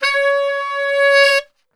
C#3 SAXSWL.wav